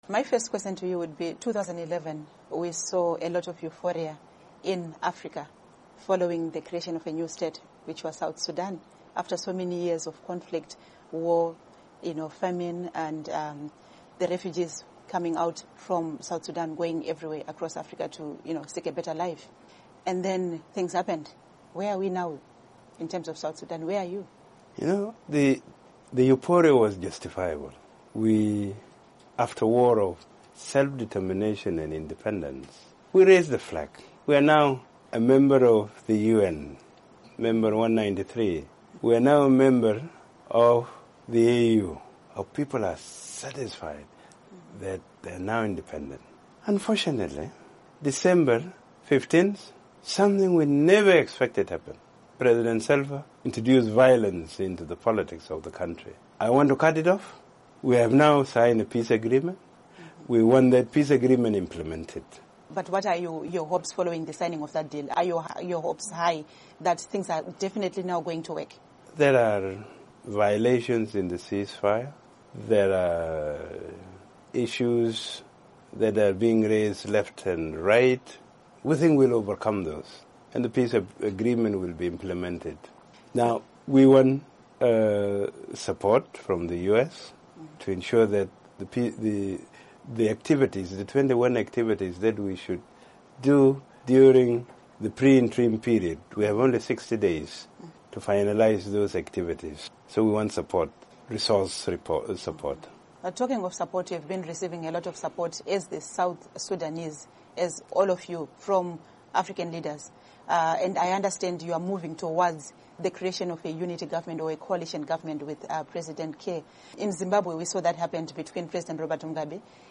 Interview With South Sudan's Rebel Leader, Riek Machar